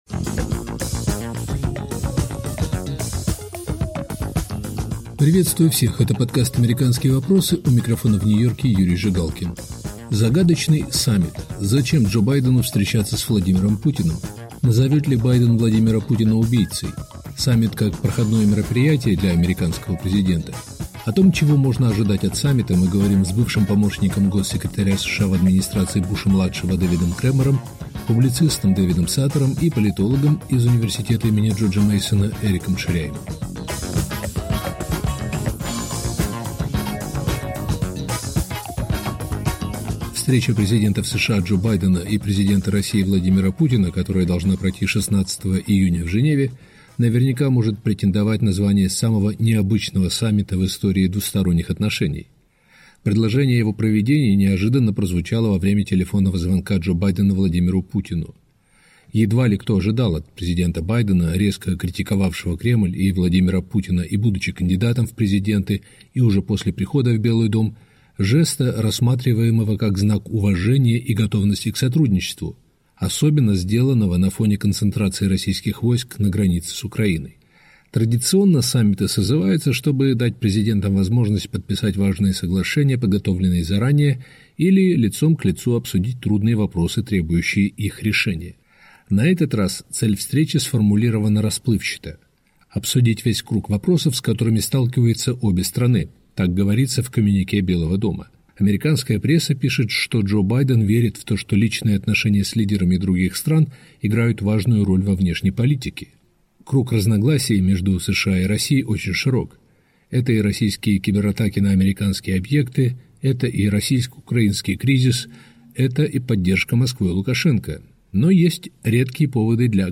Зачем Джо Байдену встречаться с Владимиром Путиным? Назовет ли Джо Байден Владимира Путина убийцей? О том, чего можно ожидать от саммита, мы говорим с бывшим помощником госсекретаря США Дэвидом Кремером